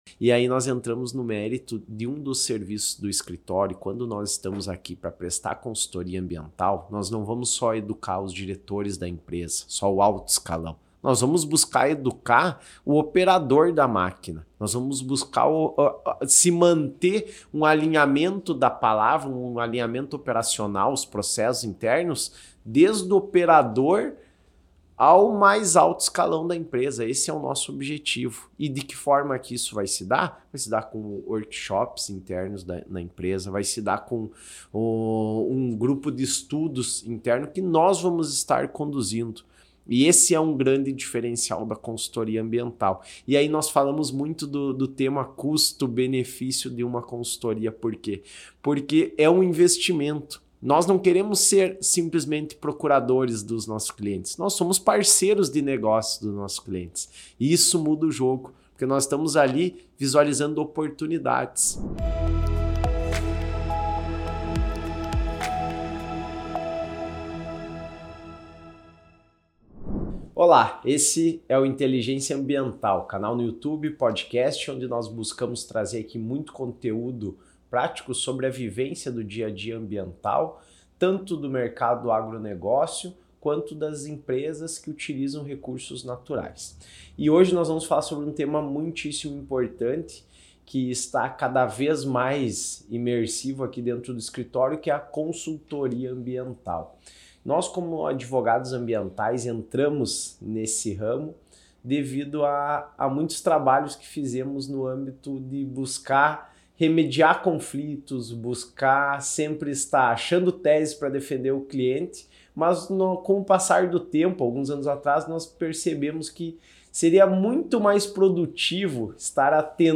participam de uma discussão aprofundada sobre a importância da consultoria ambiental. Exploramos como a consultoria não apenas fortalece a imagem das empresas, mas também oferece benefícios econômicos significativos.